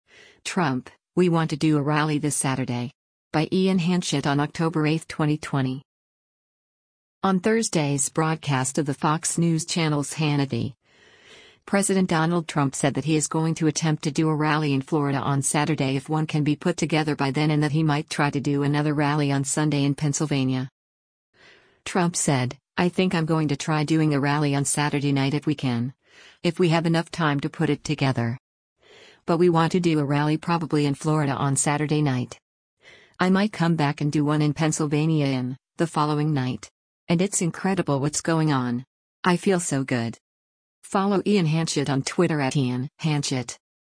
On Thursday’s broadcast of the Fox News Channel’s “Hannity,” President Donald Trump said that he is going to attempt to do a rally in Florida on Saturday if one can be put together by then and that he might try to do another rally on Sunday in Pennsylvania.